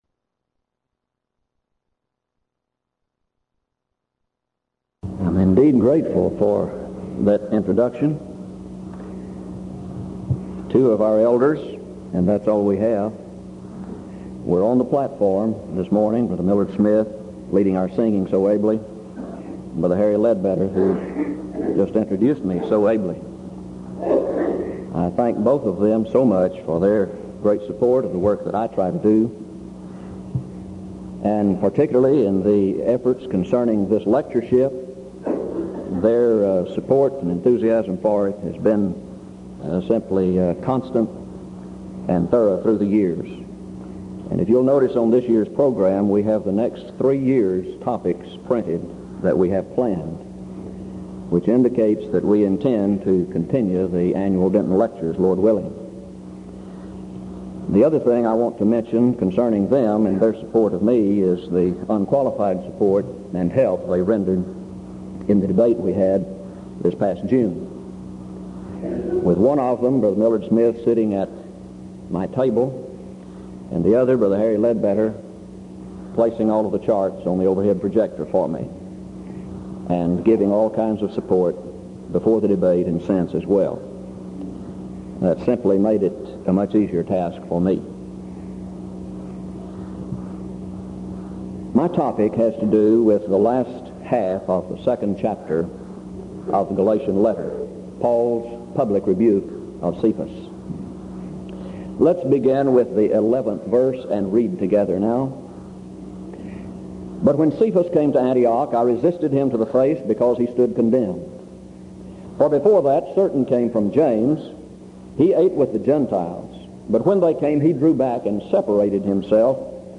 Event: 1986 Denton Lectures Theme/Title: Studies in Galatians
lecture